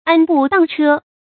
注音：ㄢ ㄅㄨˋ ㄉㄤ ㄔㄜ
讀音讀法：
安步當車的讀法